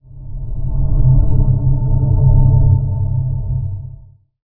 rumble2.wav